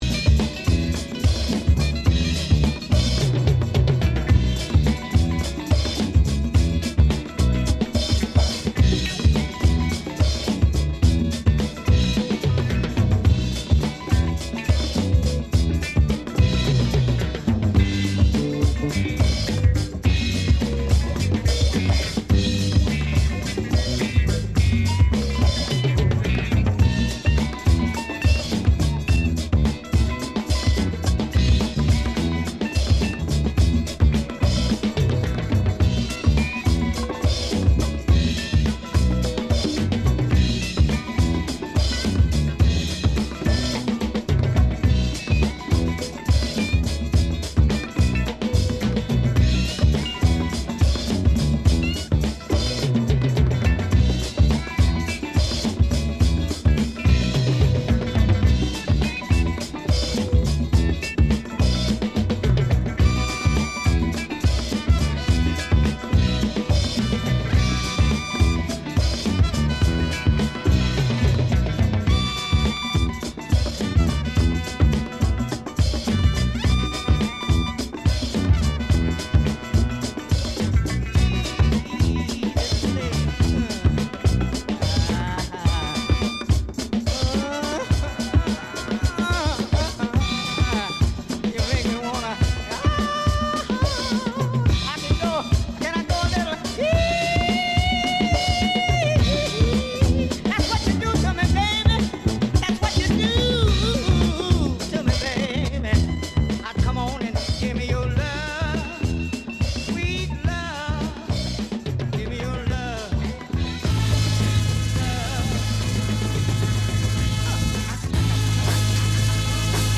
Concerti